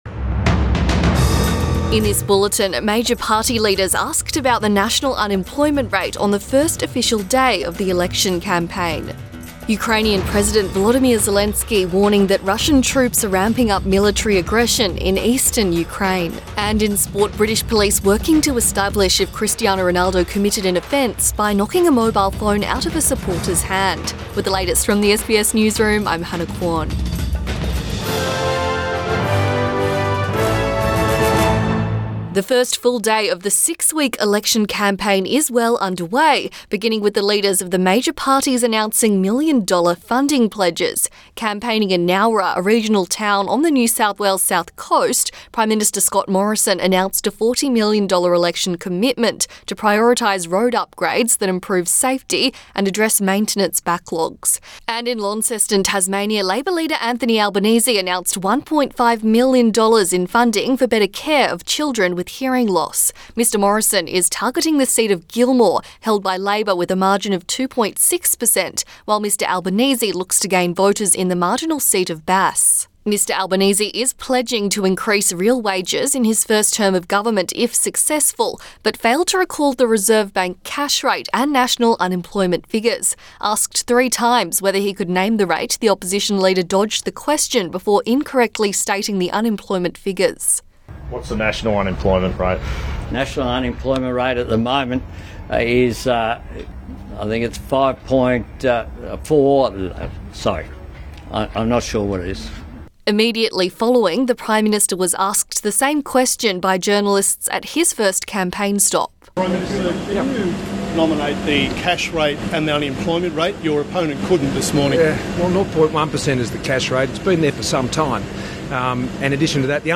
Midday bulletin 11 April 2022